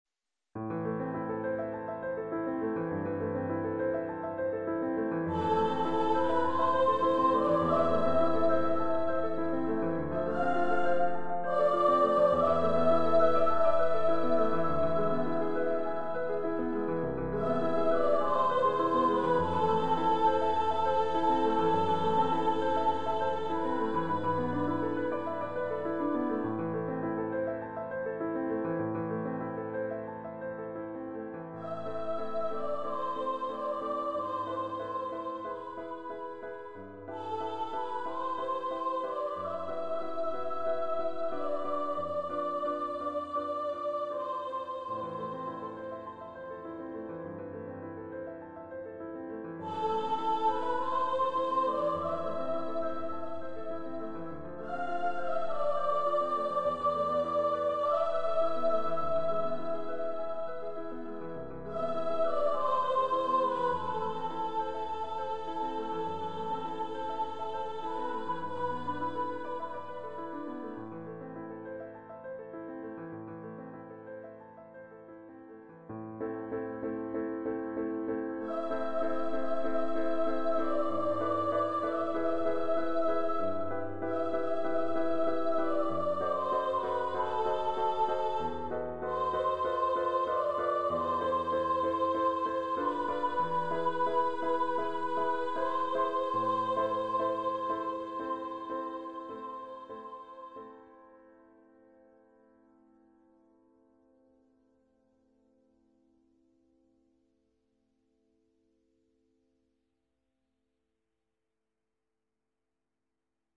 Voice, Piano
Composer's Demo